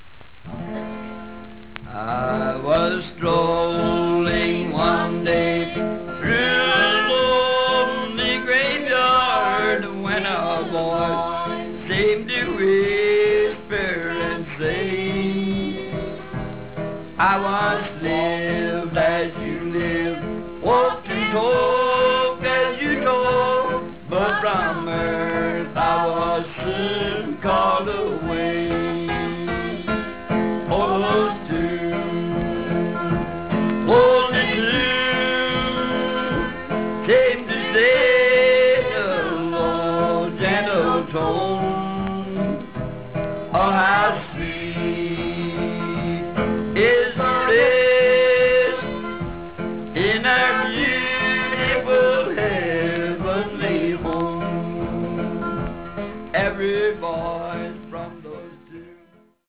White Spirituals
Camp-meeting spirituals like "Wondrous Love" have simpler texts, frequent repetitions, refrains, and inserted tag lines: they're easily taught by "lining out." Often using old-fashioned modal or gapped scales, they were published in shape-note books and were sung mostly in rural areas.